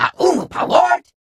Unggoy voice clip from Halo: Reach.
Grunt_entervcl_drvr_7.ogg